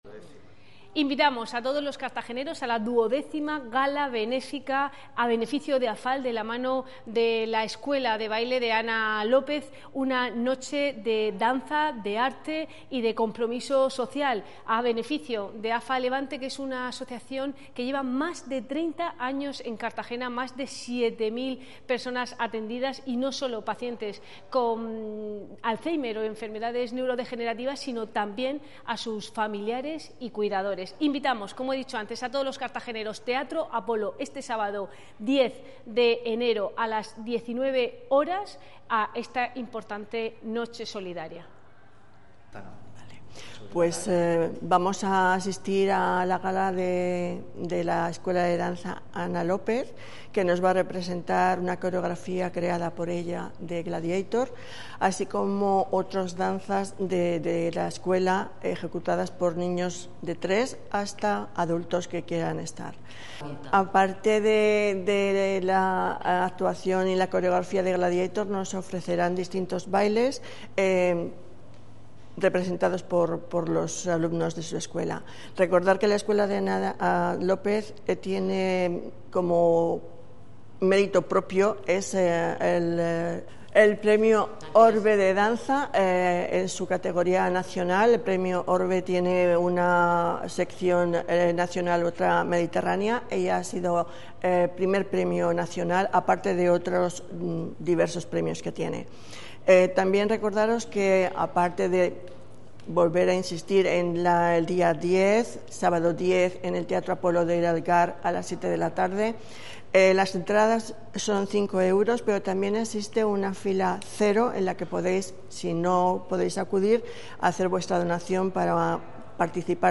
Enlace a Declaraciones de la concejala Cristina Mora